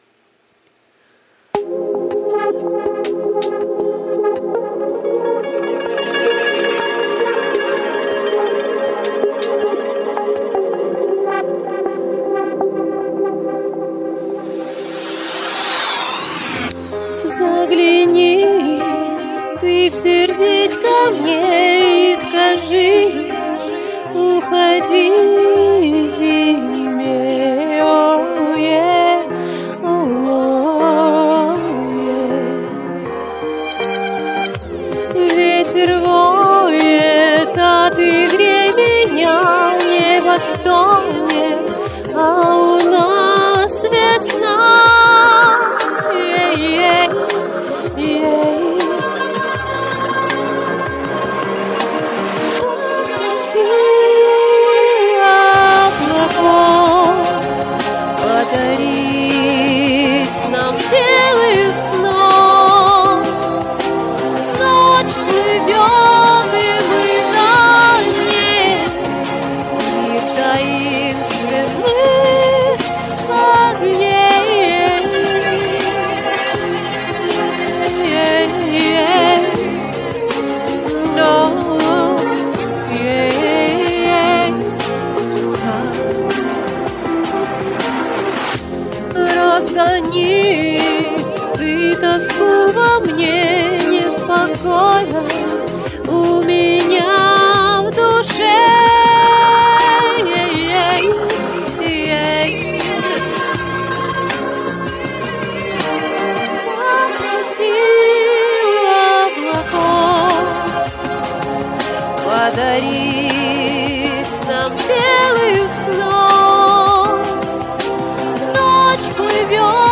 Это вживую отлично бы звучало.